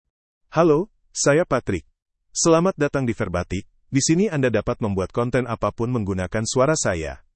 PatrickMale Indonesian AI voice
Patrick is a male AI voice for Indonesian (Indonesia).
Voice sample
Male
Patrick delivers clear pronunciation with authentic Indonesia Indonesian intonation, making your content sound professionally produced.